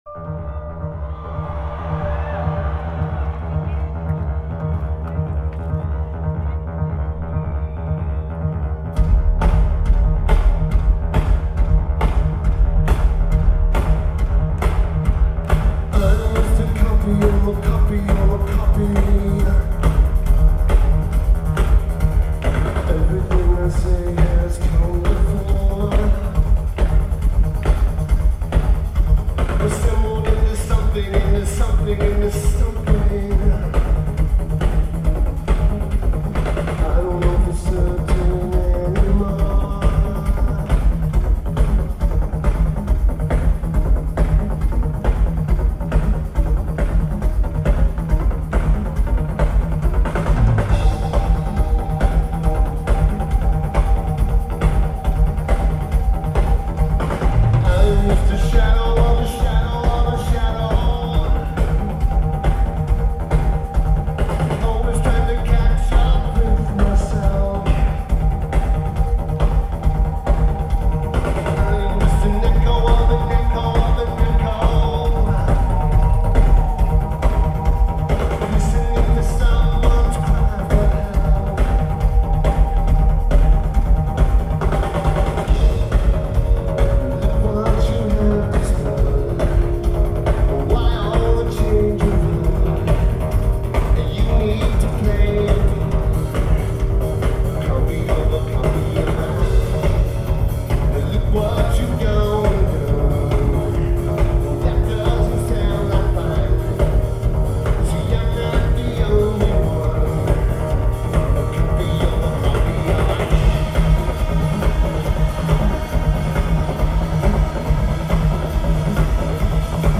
Lineage: Audio - AUD, Unknown Audience Recording